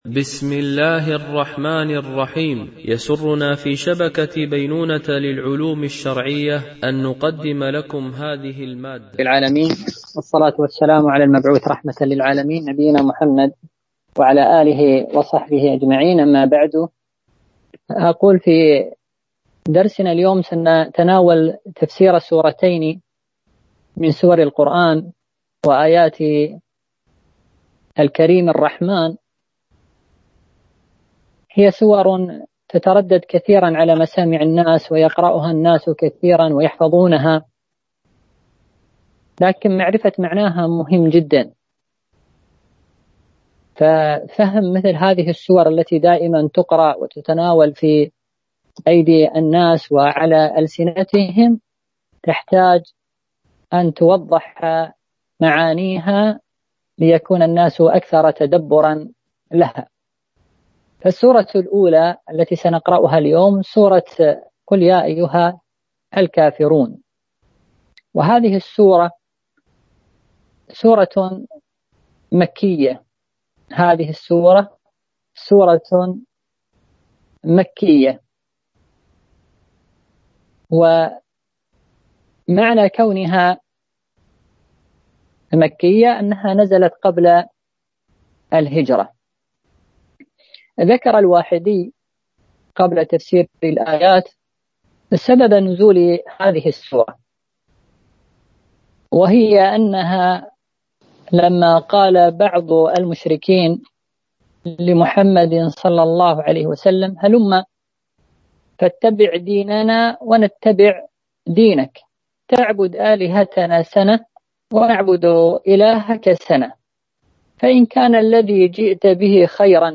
سلسلة محاضرات في تفسير القرآن الكريم - المحاضرة 4 (سورتي الكافرون والإخلاص)